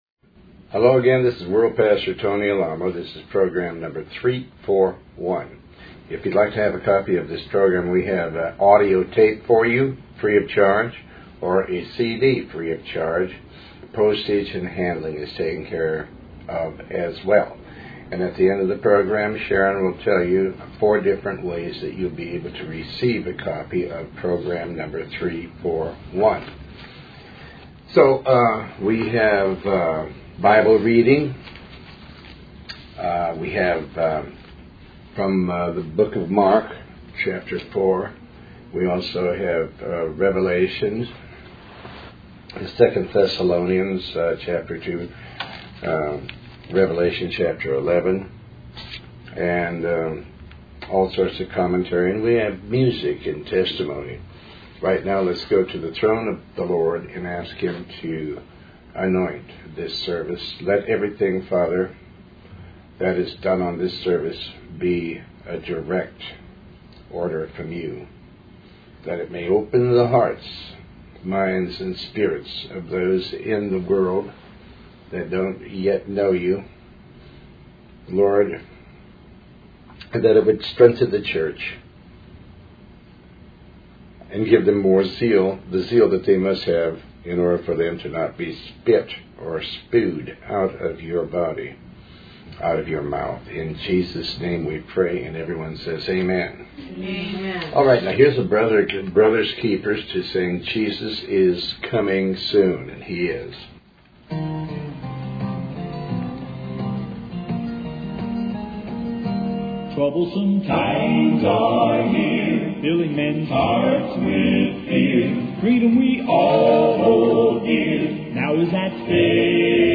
Talk Show Episode
Show Host Pastor Tony Alamo